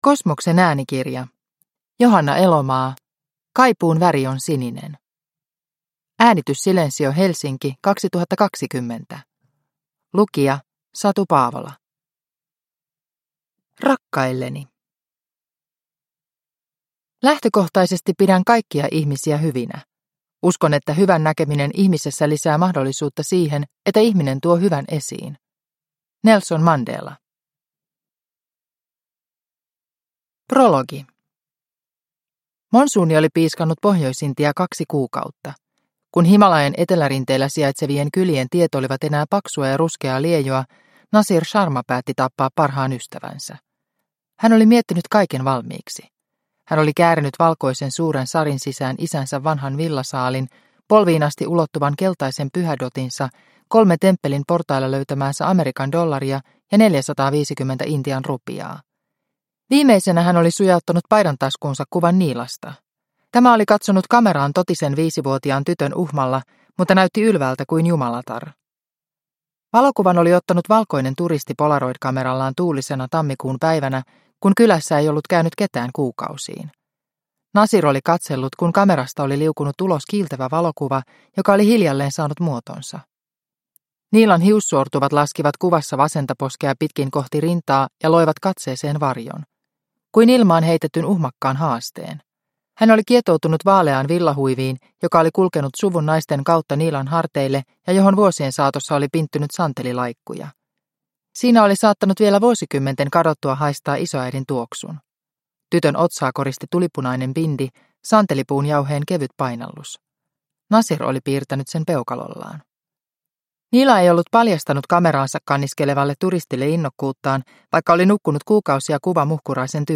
Kaipuun väri on sininen – Ljudbok – Laddas ner